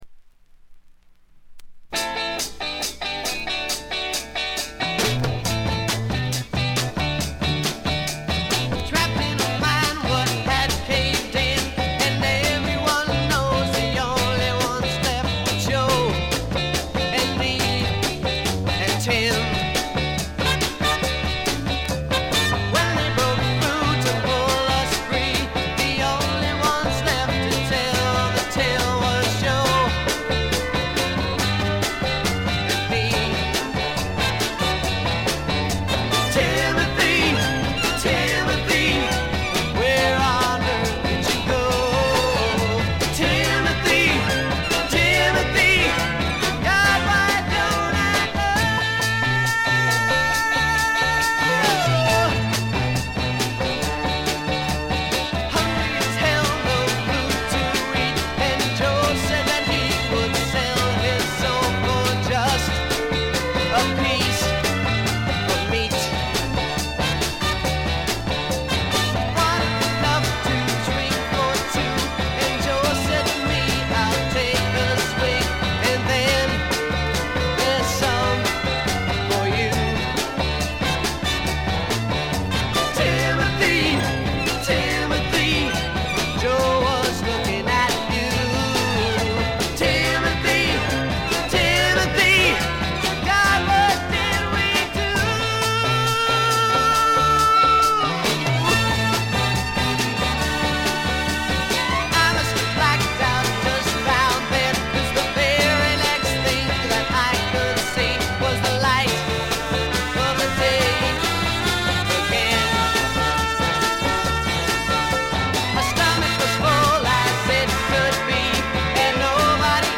部分試聴ですがほとんどノイズ感無し。
試聴曲は現品からの取り込み音源です。